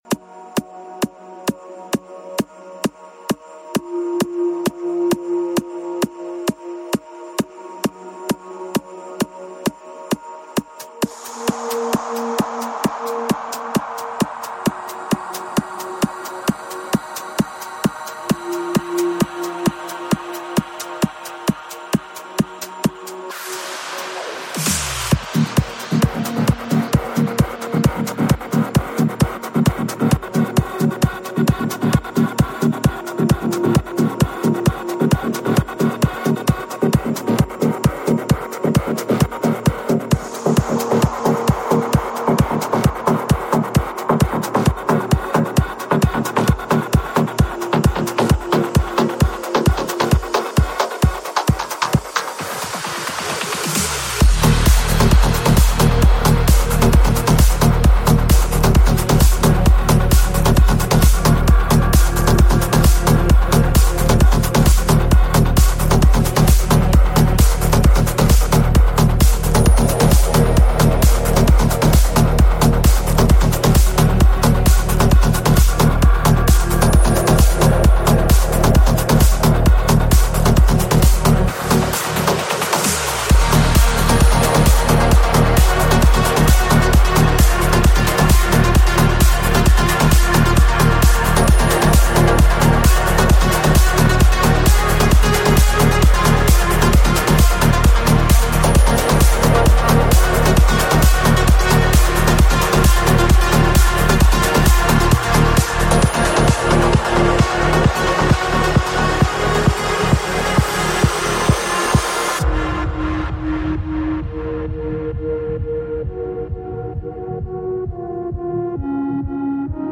Also find other EDM Livesets, DJ
Liveset/DJ mix